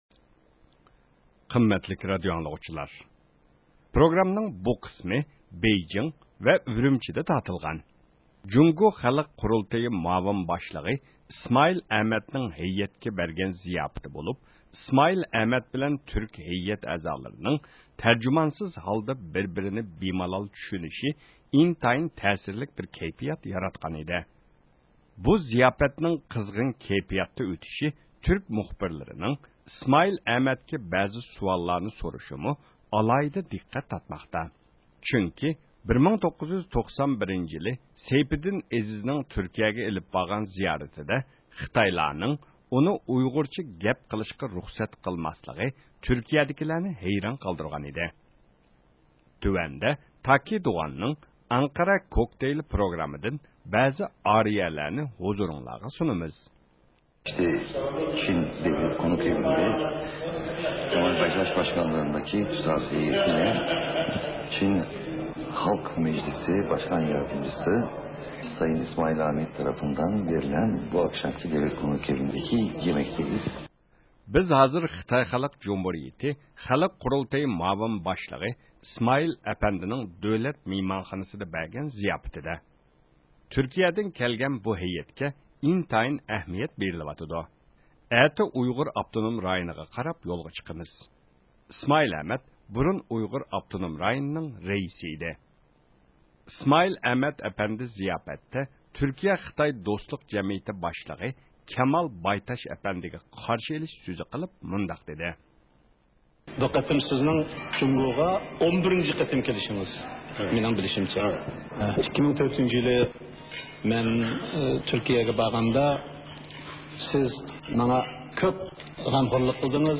پروگراممىڭ بۇ قىسمى بېيجىڭ ۋە ئۈرۈمچىدە تارتىلغان.